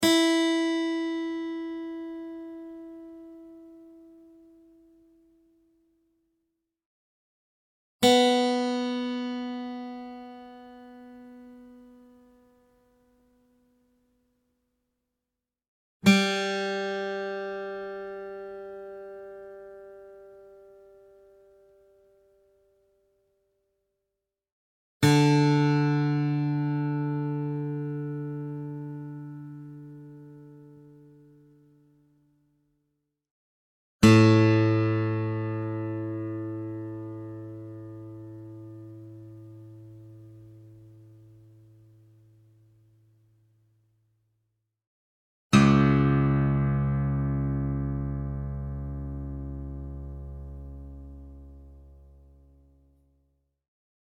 Real acoustic guitar sounds in Drop C# Tuning
Guitar Tuning Sounds